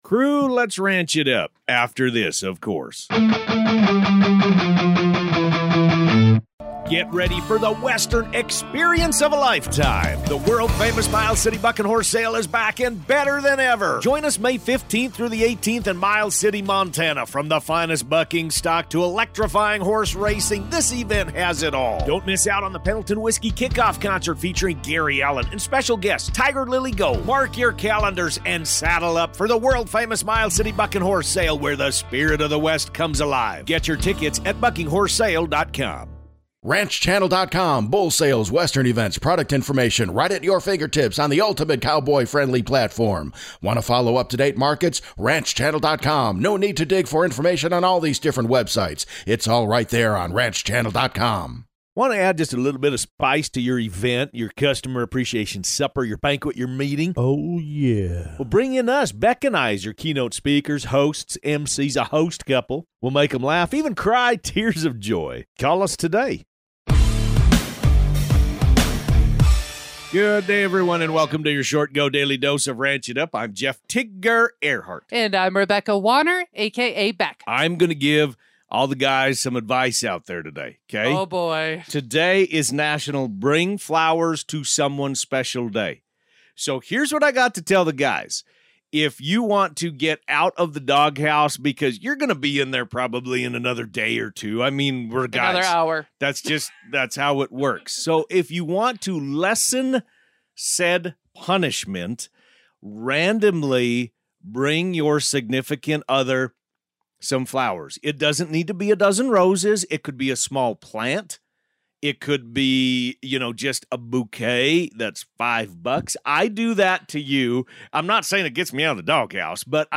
Expect insightful (and hilarious) commentary, listener shout-outs, and everything you need to stay in the loop on all things ranch. It's your daily squeeze of ranchy goodness!